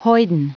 Prononciation du mot hoyden en anglais (fichier audio)
Prononciation du mot : hoyden